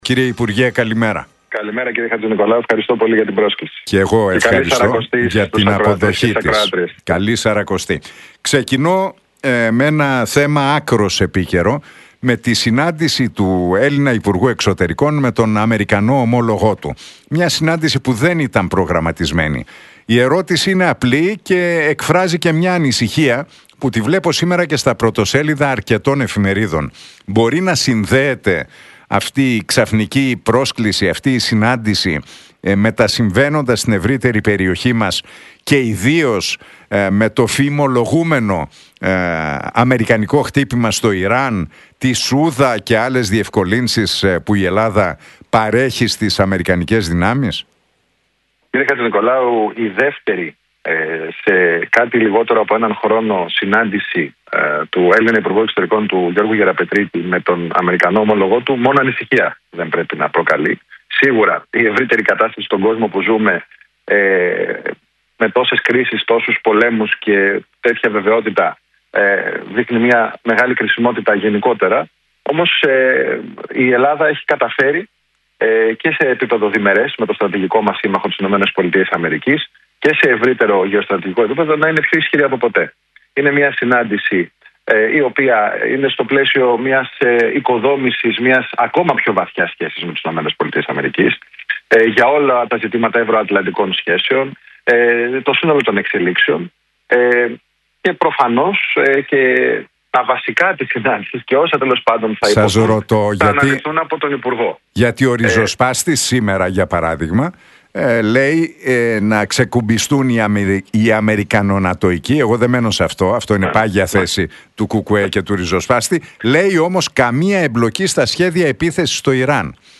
Για τη συνάντηση του Γιώργου Γεραπετρίτη με τον Αμερικανό υπουργό Εξωτερικών, Μάρκο Ρούμπιο αλλά και την υπόθεση του ΟΠΕΚΕΠΕ μετά τα πορίσματα που κατέθεσαν τα κόμματα μίλησε ο Κυβερνητικός Εκπρόσωπος, Παύλος Μαρινάκης στον Realfm 97,8 και την εκπομπή του Νίκου Χατζηνικολάου.